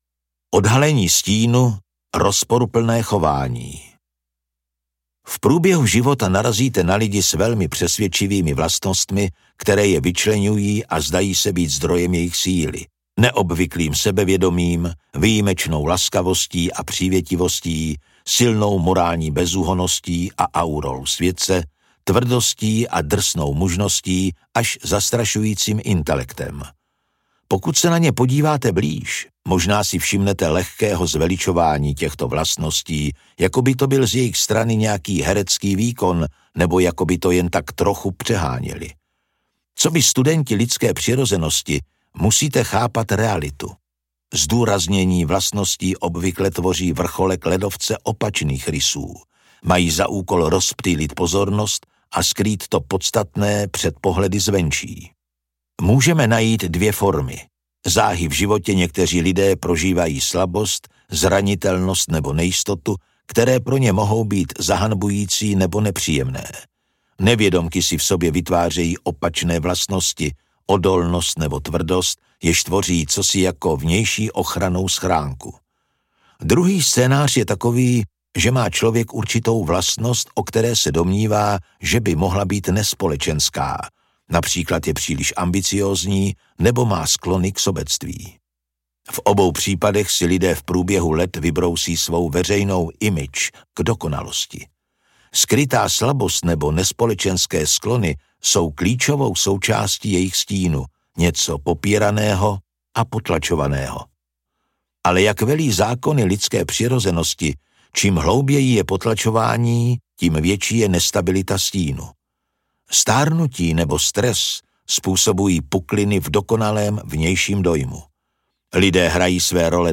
Zákony lidské přirozenosti audiokniha
Ukázka z knihy
zakony-lidske-prirozenosti-audiokniha